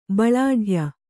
♪ baḷāḍhya